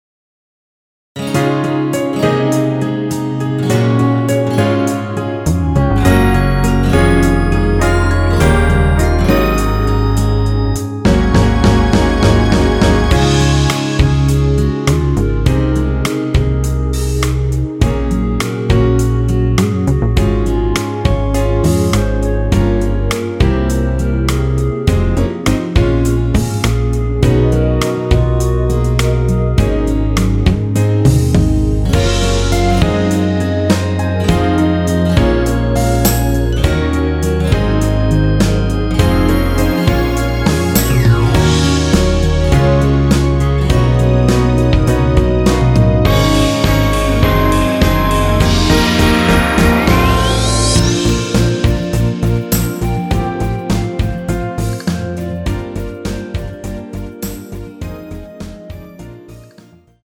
원키에서(-1)내린 멜로디 포함된 MR입니다.(미리듣기 확인)
Db
앞부분30초, 뒷부분30초씩 편집해서 올려 드리고 있습니다.
중간에 음이 끈어지고 다시 나오는 이유는